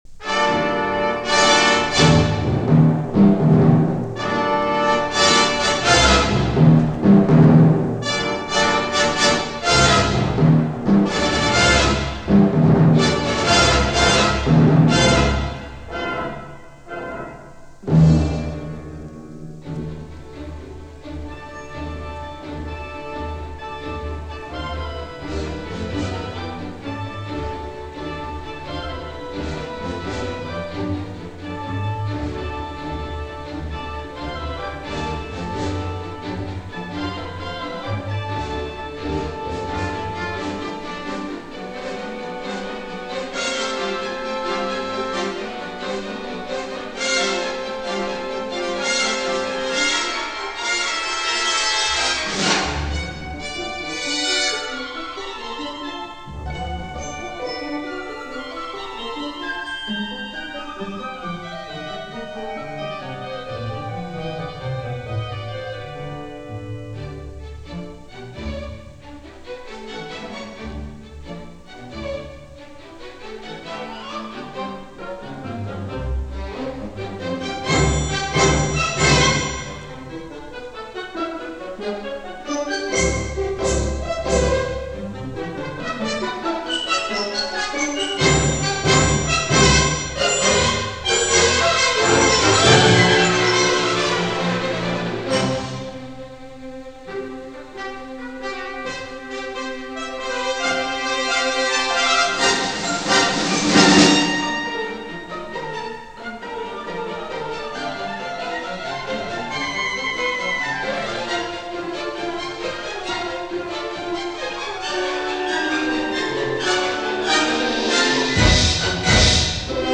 for Large Orchestra
a piece for large symphony orchestra